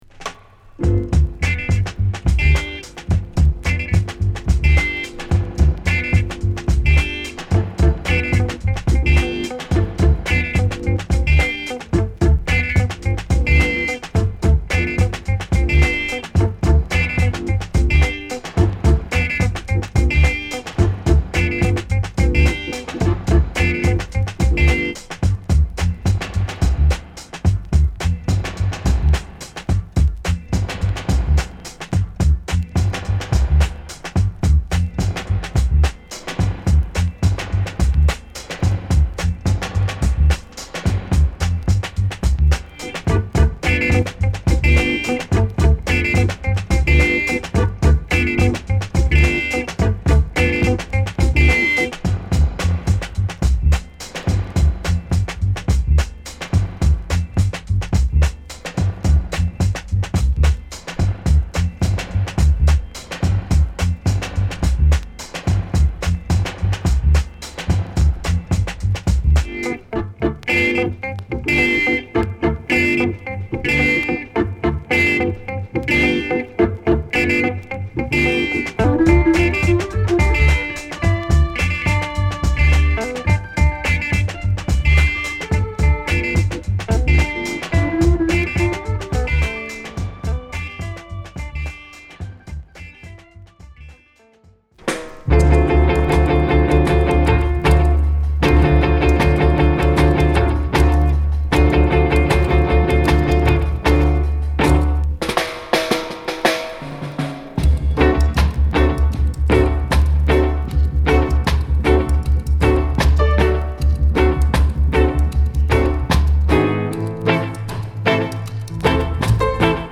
極太のベースに軽快なドラム、ファンキーなギターを配したジャマイカンファンク〜レアグルーヴチューン！